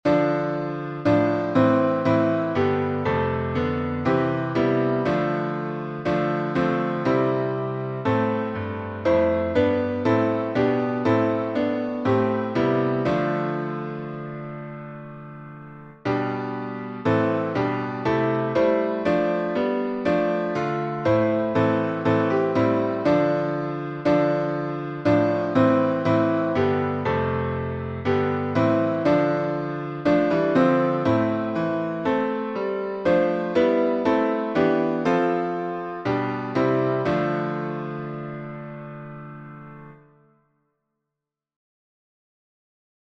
What Wondrous Love Is This — Modified Chording.
Key signature: C major (no sharps or flats) Time signature: 2/2